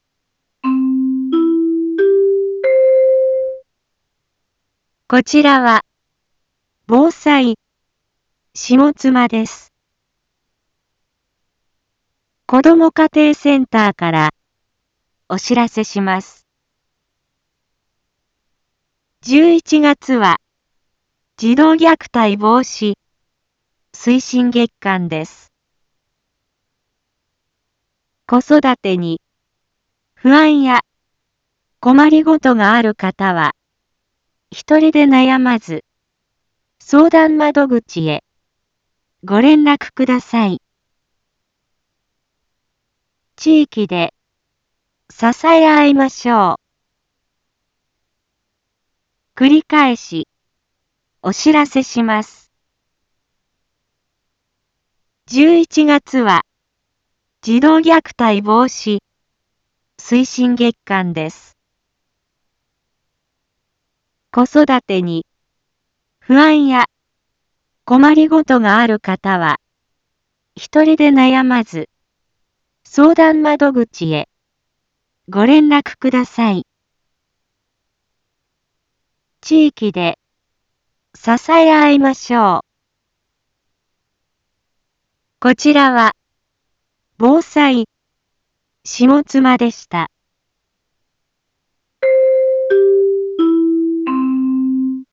一般放送情報
Back Home 一般放送情報 音声放送 再生 一般放送情報 登録日時：2025-11-15 09:01:55 タイトル：児童虐待防止推進月間 インフォメーション：こちらは、ぼうさいしもつまです。